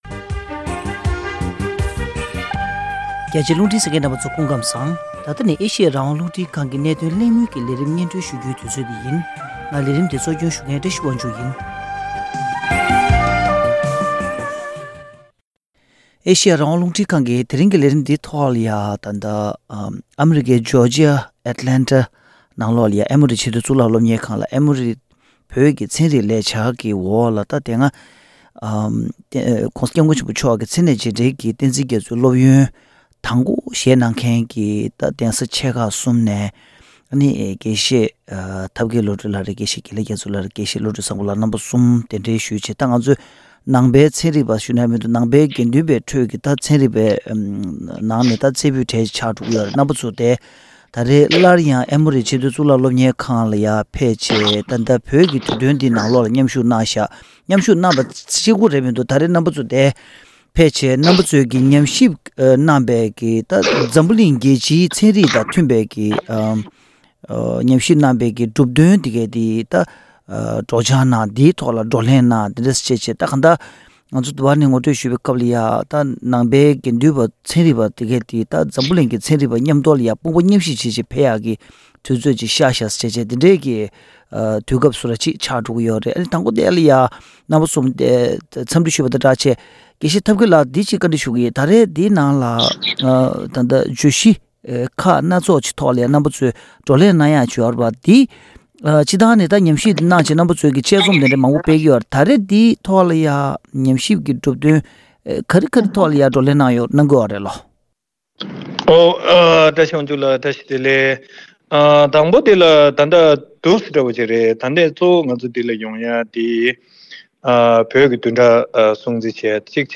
འཕྲོད་བསྟེན་གྱི་ནང་དུ་སྙིང་རྗེ་གོང་འཕེལ་གཏོང་རྒྱུའི་ཕན་ནུས་སྐོར་བགྲོ་གླེང་གནང་ཡོད་པའི་སྐོར།